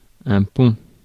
Ääntäminen
France: IPA: [œ̃ pɔ̃] Tuntematon aksentti: IPA: /pɔ̃/